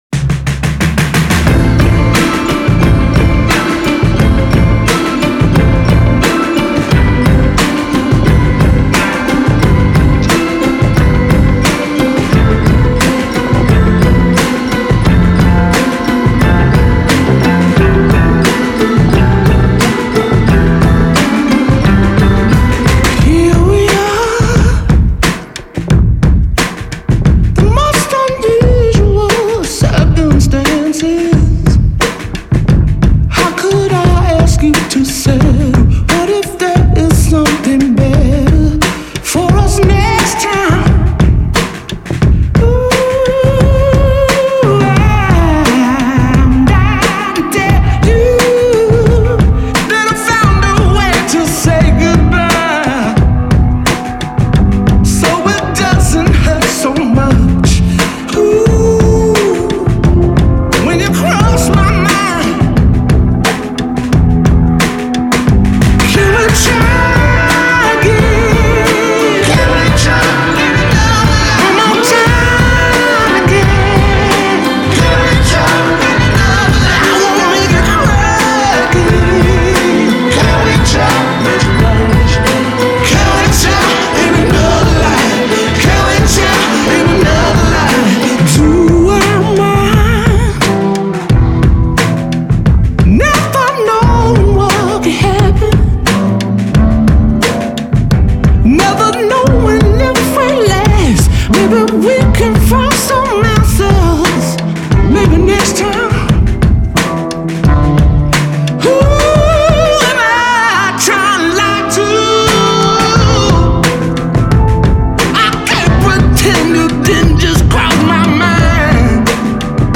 Vocal powerhouse